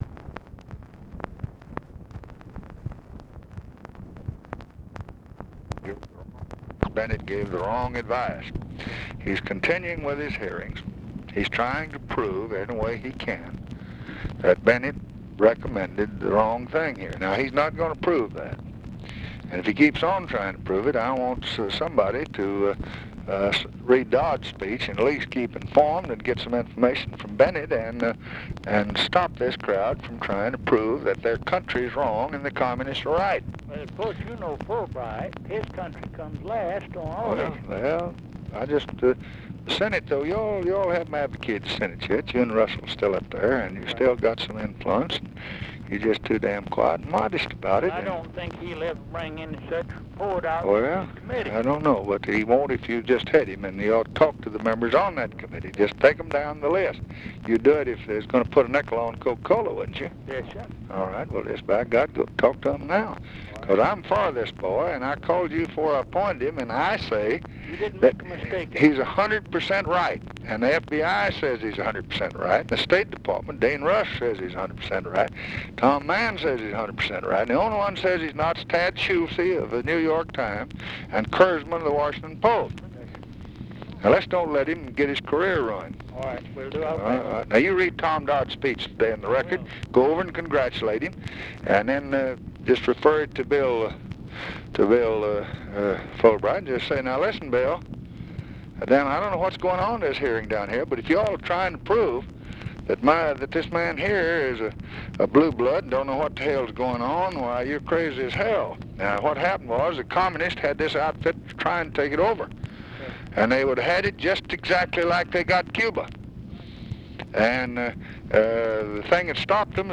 Conversation with HERMAN TALMADGE, August 23, 1965
Secret White House Tapes